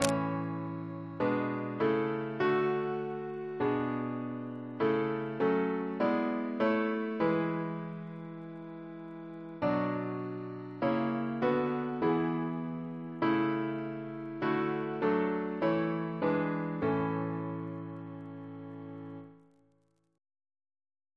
Double chant in A Composer: James Turle (1802-1882), Organist of Westminster Abbey Reference psalters: ACB: 54; CWP: 34; H1940: 634; H1982: S178; OCB: 25; PP/SNCB: 58; RSCM: 138